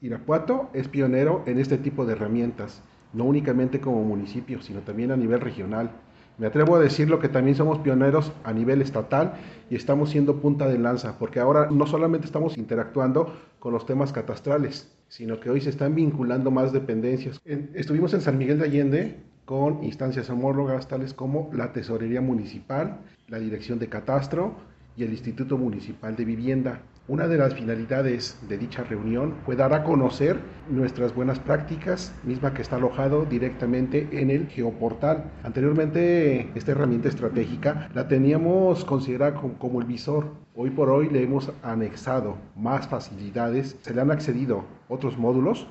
AudioBoletines
Miguel Ángel Fonseca Gutiérrez – Tesorero de Irapuato